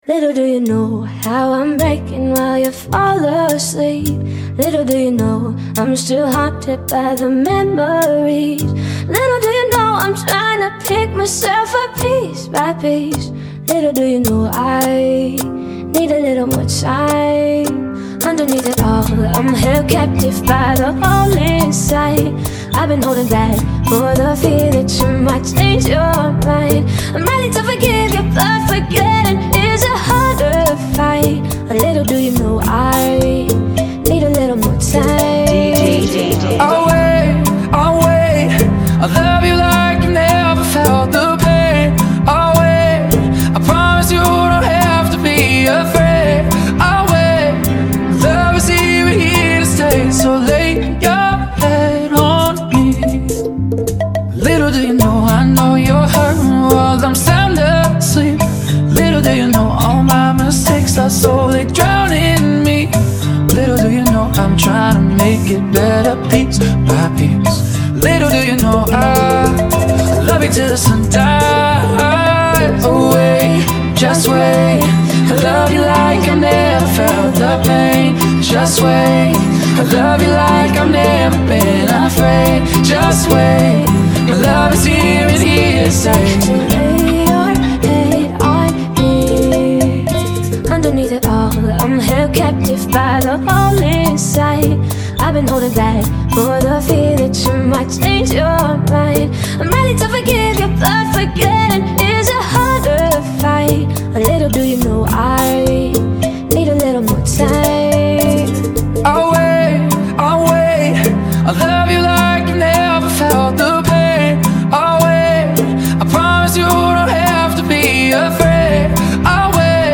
106 BPM
Genre: Bachata Remix